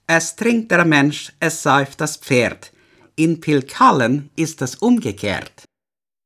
Hörproben Dialekte
Ostpreußisch
65Ostpreussisch-Dialekt.mp3